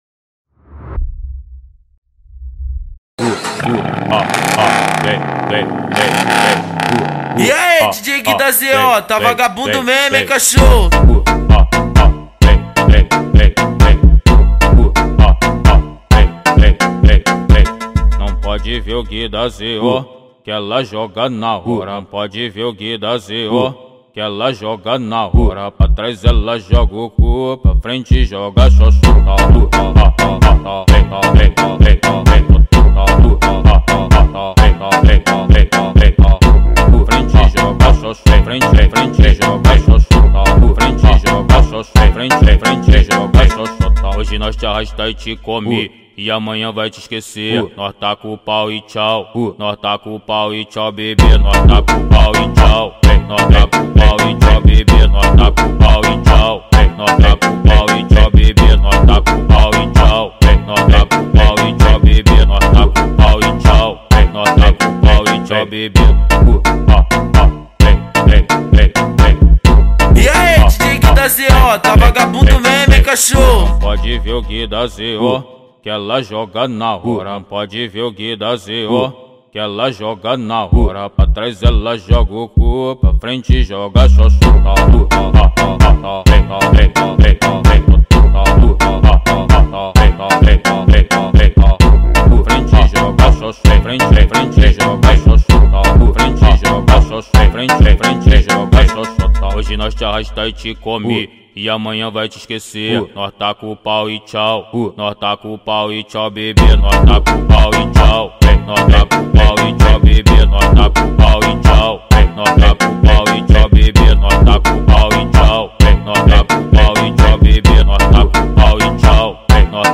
2024-10-18 22:35:19 Gênero: Funk Views